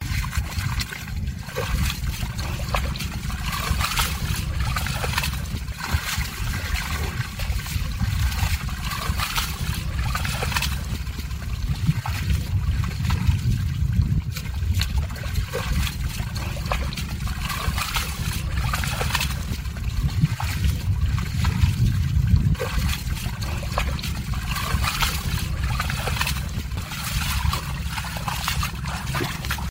Tiếng Trâu, bò… đi Cày ruộng, Bừa ruộng, làm đồng…
Thể loại: Tiếng động
Description: Tiếng bước chân đều đều trên cánh đồng mênh mông, tiếng trâu, bò lao xao kéo cày, kéo bừa....
tieng-trau-bo-di-cay-ruong-bua-ruong-lam-dong-www_tiengdong_com.mp3